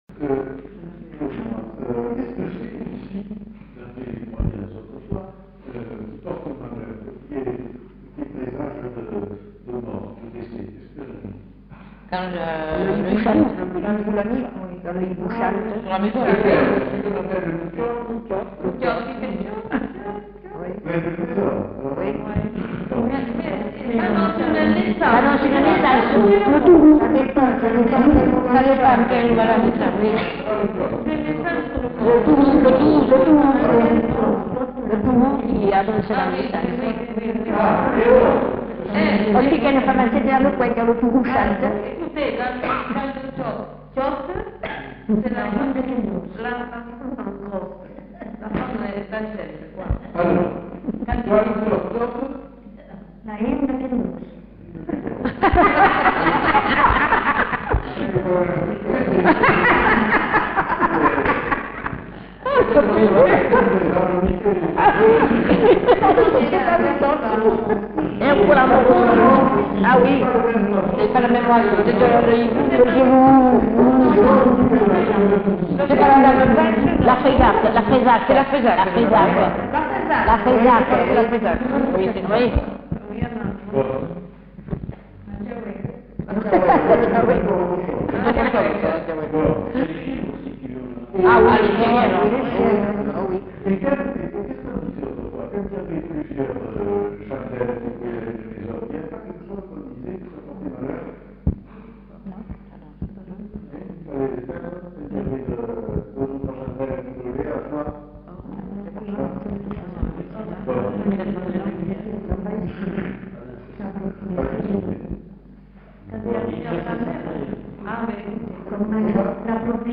Lieu : Villandraut
Genre : témoignage thématique
Type de voix : voix de femme Production du son : récité